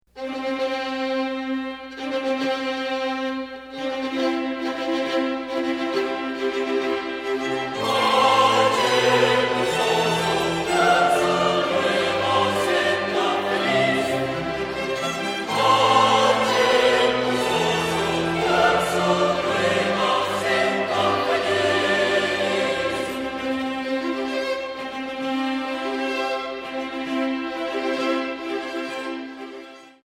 Otras fueron realizadas en "vivo" durante alguna de nuestras presentaciones.